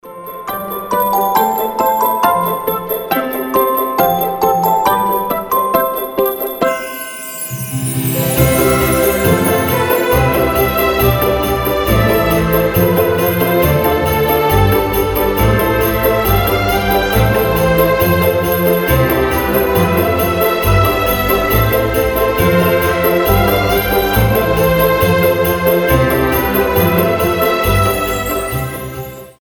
• Качество: 320, Stereo
без слов
добрые
праздничные
колокольчики
рождественские